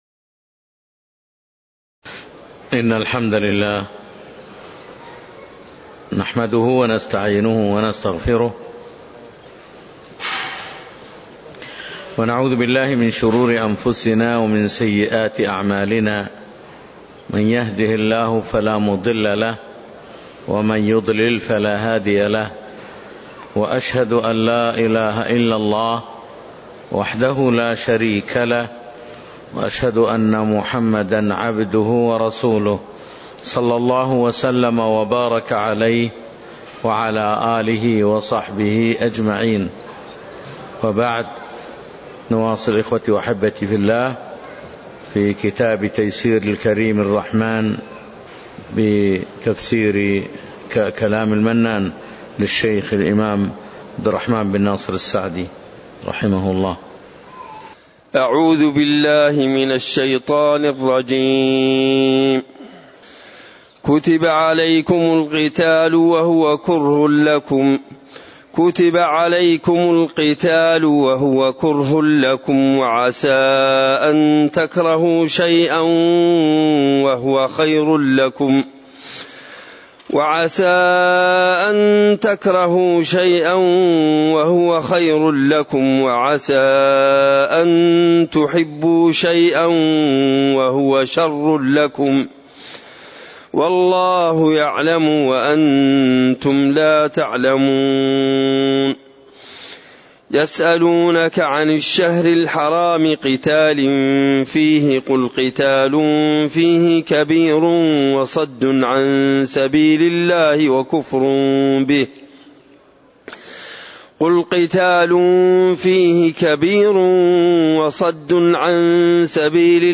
تاريخ النشر ٢٣ محرم ١٤٣٩ هـ المكان: المسجد النبوي الشيخ